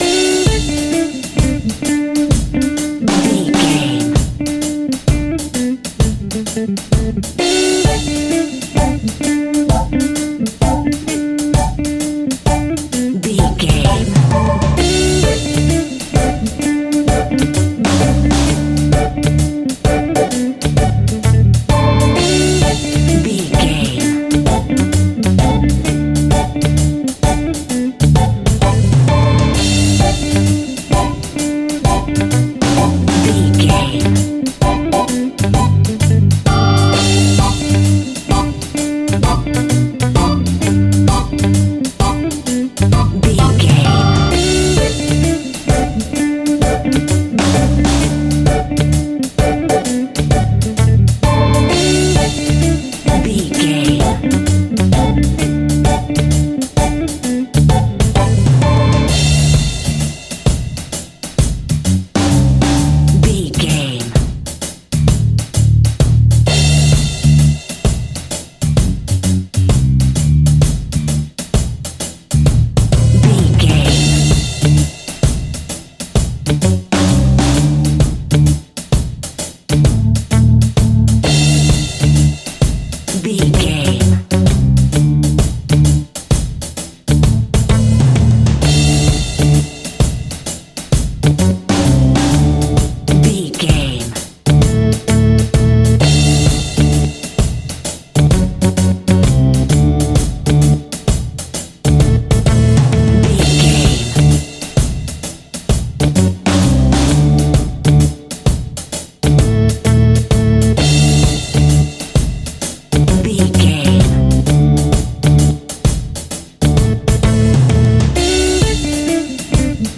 Ionian/Major
D
laid back
chilled
off beat
drums
skank guitar
hammond organ
percussion
horns